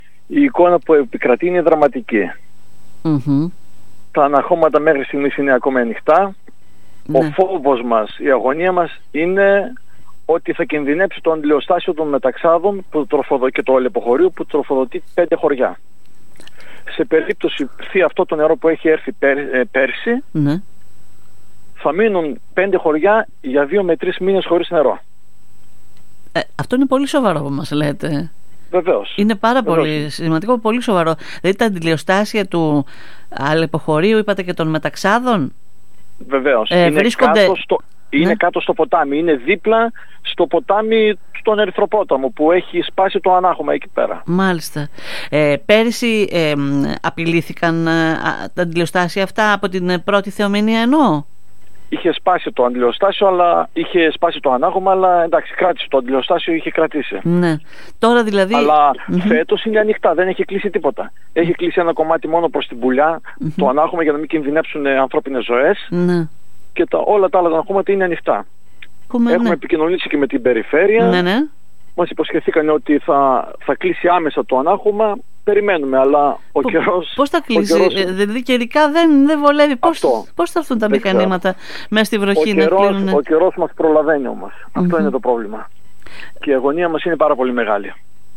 Τον φόβο και την αγωνία ότι σε μια επικείμενη θεομηνία θα κινδυνέψουν τα αντλιοστάσια των χωριών Μεταξάδων και Αλεποχωρίου εξαιτίας των ανοιχτών αναχωμάτων εξέφρασε μιλώντας στην ΕΡΤ Ορεστιάδας ο πρόεδρος της κοινότητας Μεταξάδων Χρήστος Κισσούδης.